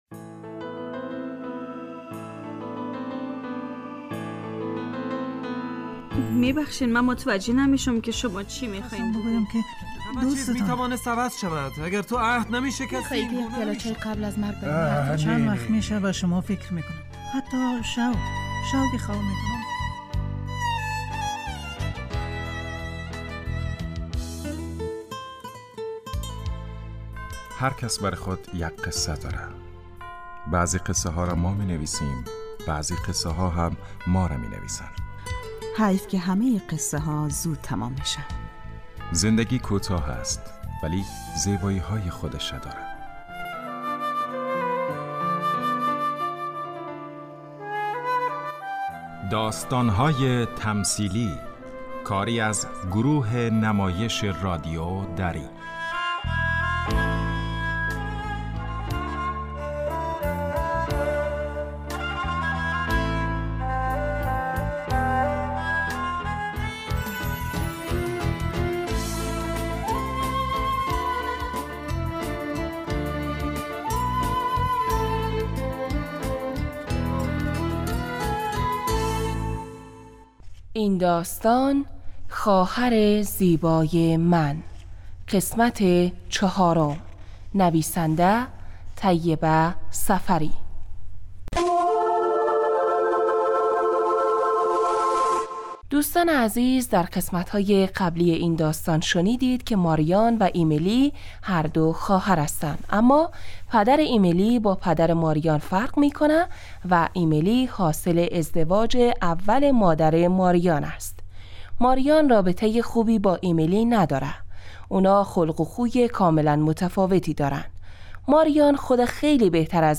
داستانهای تمثیلی نمایش 15 دقیقه ای هستند که هر روز ساعت 4:45 عصربه وقت وافغانستان پخش می شود.